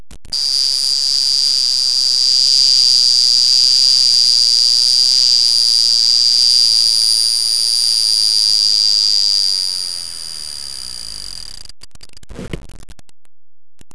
Az ébresztő külön rugóháztól van meghajtva, és teljesen felhúzva mintegy 13 másodperces hangos, a célnak okvetlenül megfelelő berregést produkál.
Az ébresztőszerkezet „lelke” egy Clement-horgonyos gátszerkezet, amely a csengő kis kalapácsát mozgatja.
PoljotAlarm2.wav